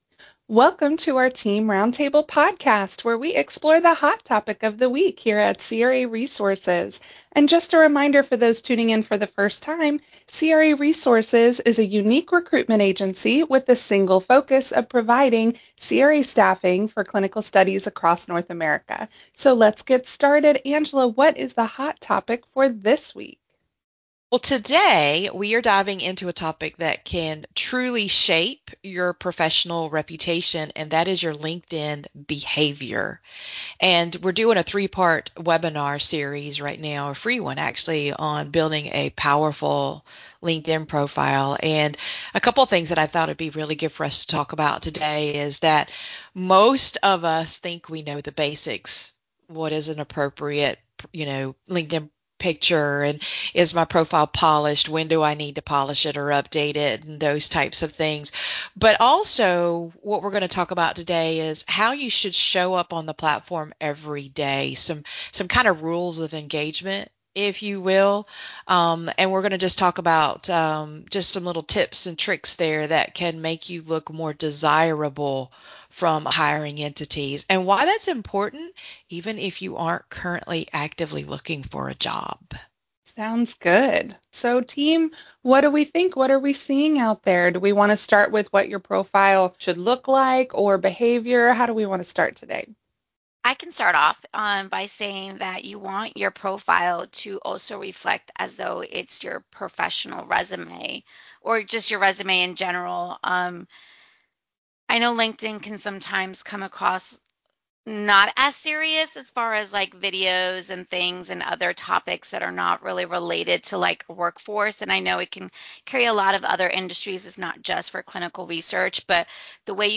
Roundtable: LinkedIn and Your Professional Reputation - craresources